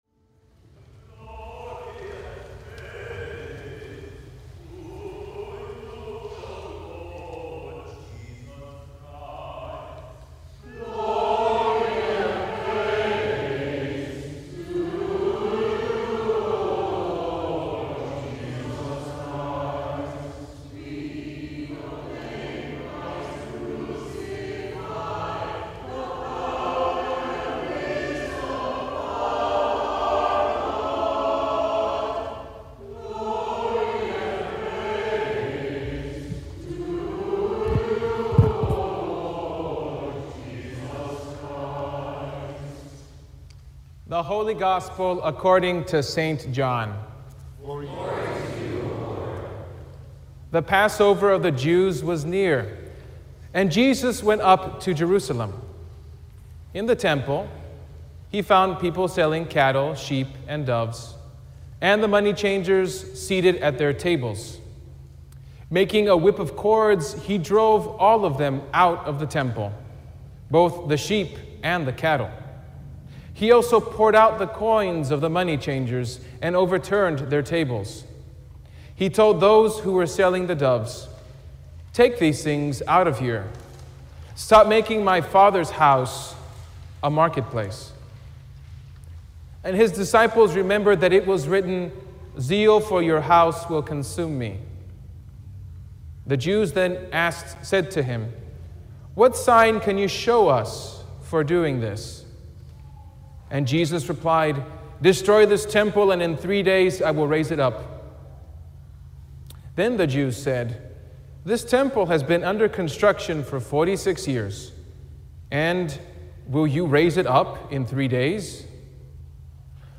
Sermon from the Third Sunday in Lent